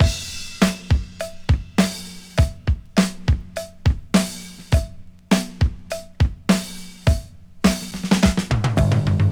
Free breakbeat - kick tuned to the F# note. Loudest frequency: 542Hz
• 103 Bpm Breakbeat F Key.wav
103-bpm-breakbeat-f-key-rnW.wav